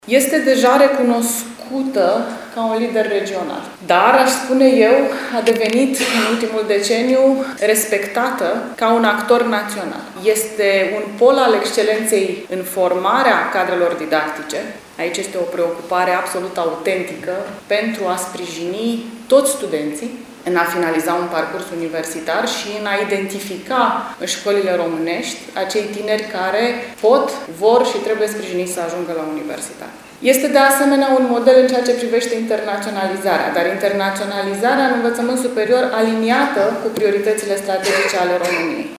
Ea a participat la întâlnirea festivă desfășurată la USV și la care au fost invitați rectori din țară, Ucraina și Republica Moldova, precum și oficialități locale și județene.